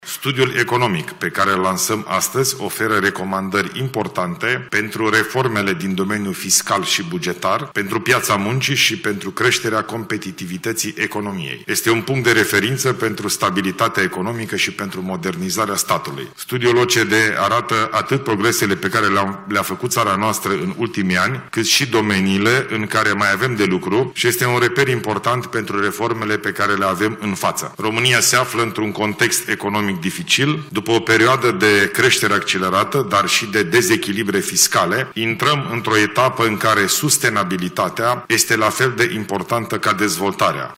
Ilie Bolojan, la lansarea Studiului Economic 2026 pentru România elaborat de OCDE: „Intrăm într-o etapă în care sustenabilitatea este la fel de importantă ca dezvoltarea”